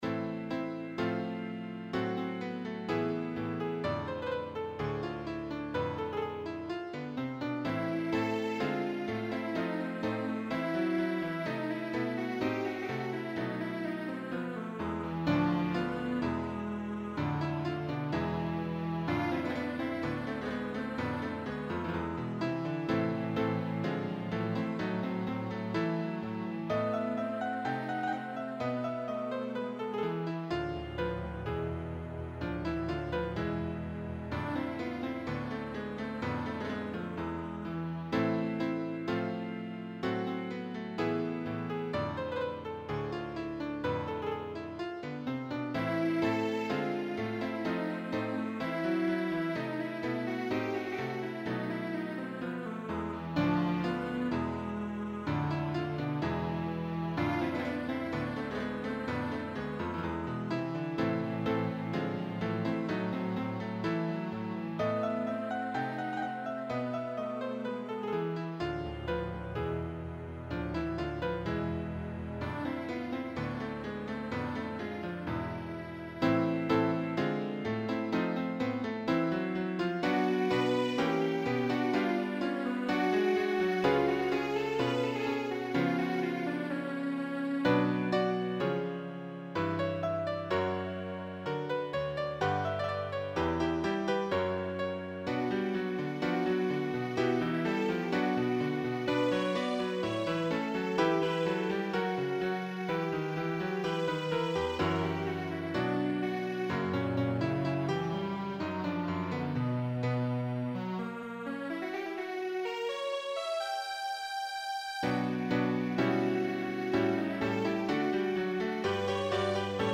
for cello and piano